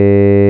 Siin kujutatud vokaalid on sünteesitud Eek & Meister (1998) andmete põhjal, kus /a/ F1 = 670 Hz, F2 = 1070 Hz ja F3 = 2460 Hz ning /e/ F1 = 435 Hz, F2 = 2010 Hz ja F3 = 2545 Hz.
synt_vok_e.wav